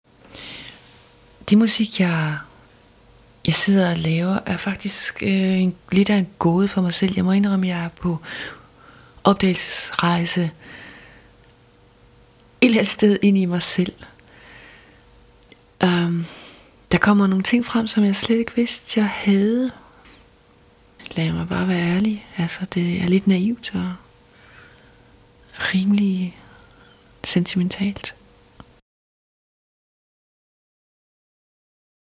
Del 1 - MP3 tale uden musik (0,5 MB, varighed 0:30)